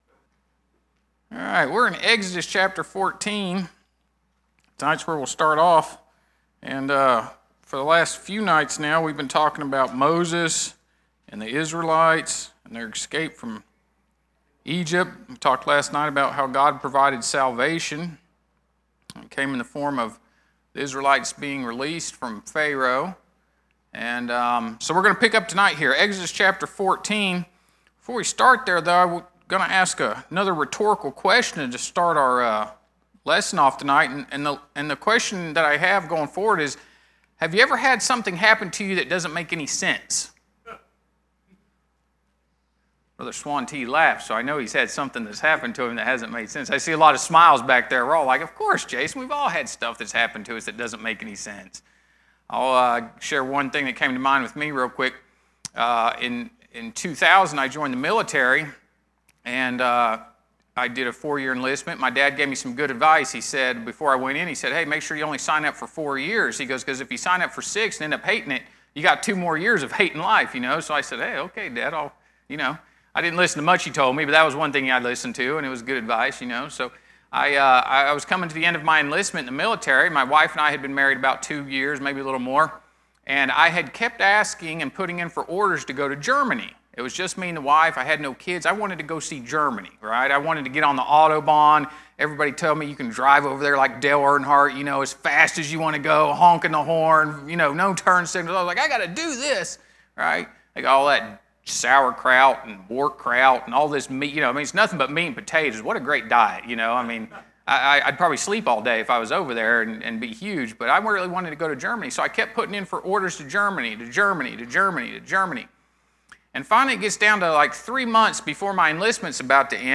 Our Adult class during VBS shares a Bible study about Moses and the Israelites.